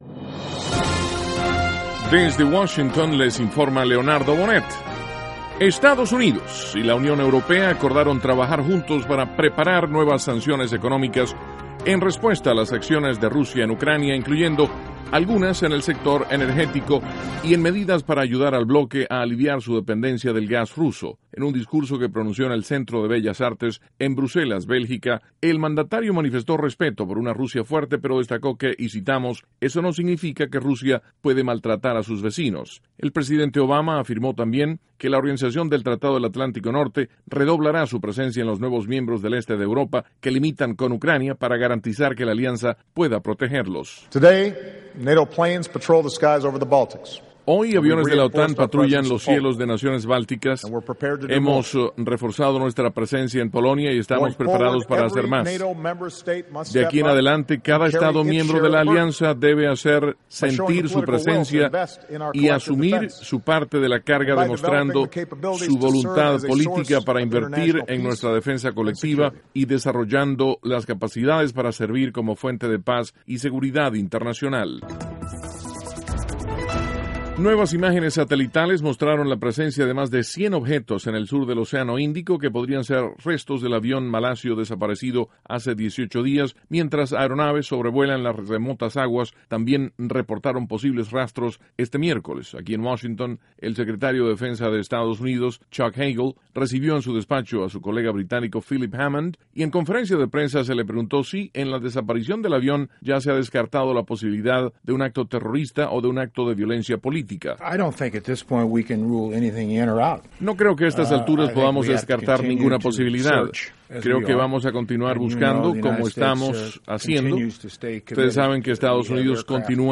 NOTICIAS - MIÉRCOLES, 26 DE MARZO, 2014
Duración: 3:25 Contenido: 1.- El presidente Obama afirma que la OTAN patrullarán cielos de naciones bálticas. (Sonido Obama) 2.- El secretario de Defensa de Estados Unidos, Chuck Hagle, comenta sobre el desaparecido avión de Malaysian Airlines. (Sonido Hagle) 3.- La Primera Dama de Estados Unidos, Michelle Obama, su madre y sus hijas, concluyen su visita a China.